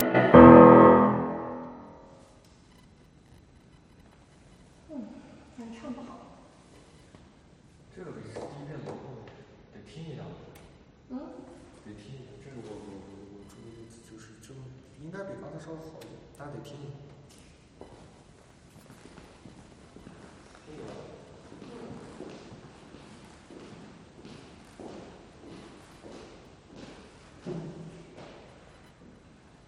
Была выездная запись на днях в помещении - писал академический вокал с роялем. на рояль стояли октавы 012 - новые корпуса с переключалками и новые головки-кардиоиды.
Так вот, рояльные микрофоны (октавы) ловили вот такие наводки (прилагаю файл), которые потом очень сложно вычистить изотопом (кстати, если кто знает способы, буду признателен).
Звук похожий издают трамваи, когда трогаются с места - за окном есть трамвайные пути..